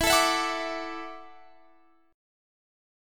Listen to E9sus4 strummed